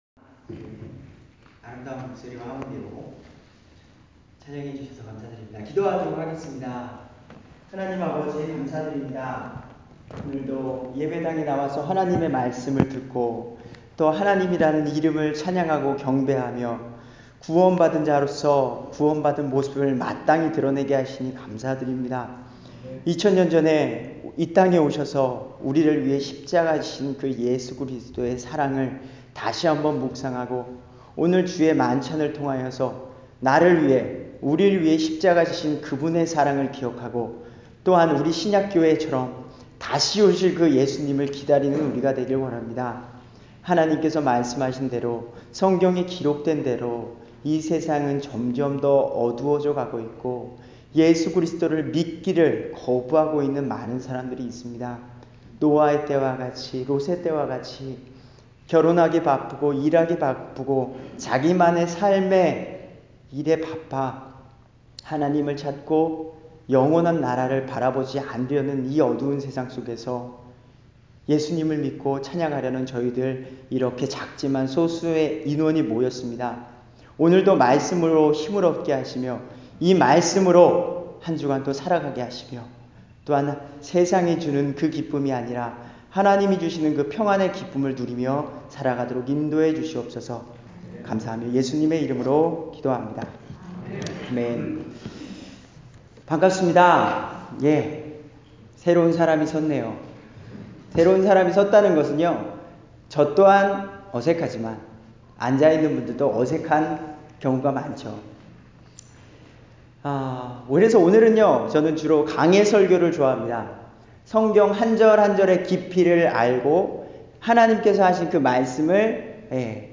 우리의 선택은?-주일설교